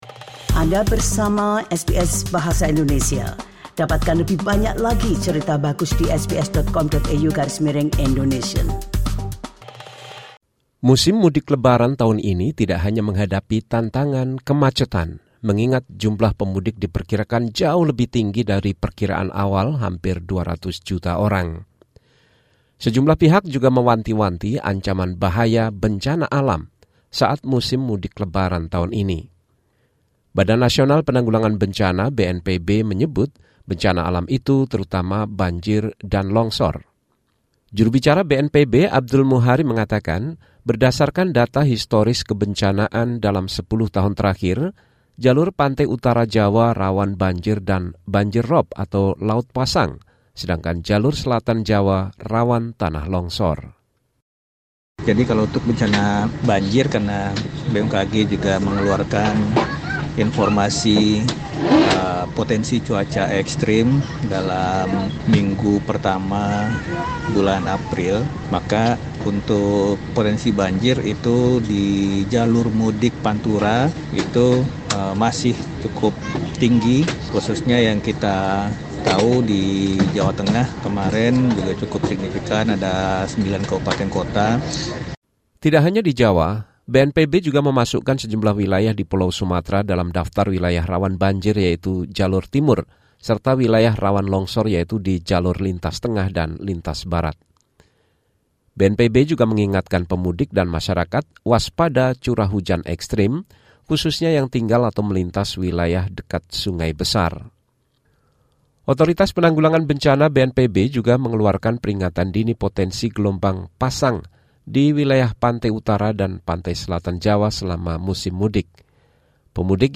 The following report from KBR 68 H reports on the posturing of the disaster during Lebaran homecoming.